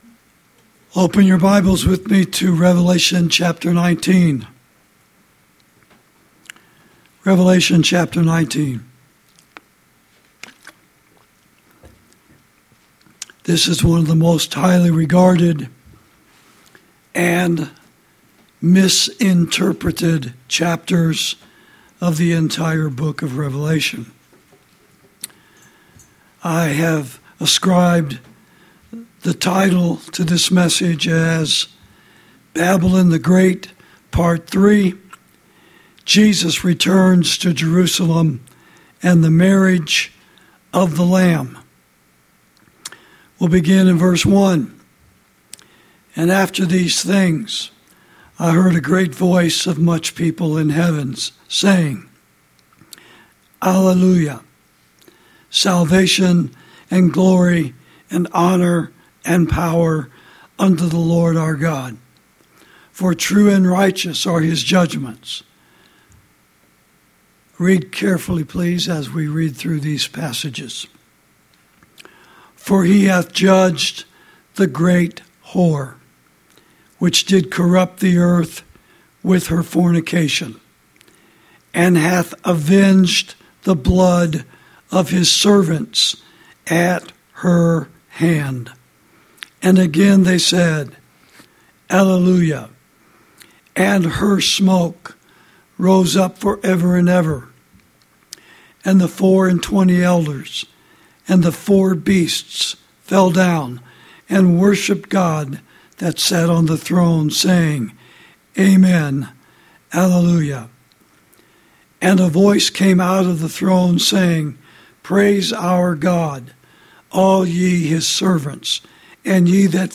Sermons > Babylon The Great — Part Three — Jesus Returns To Jerusalem And The Marriage Of The Lamb (Prophecy Message Number Twenty-Three)